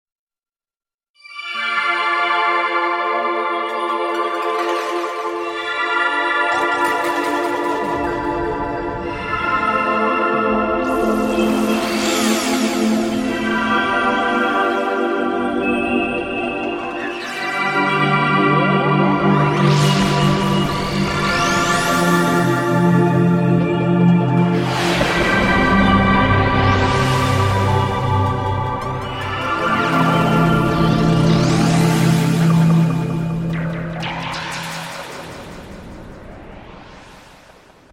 Звук, погружающий в любовь (непреодолимо затягивает)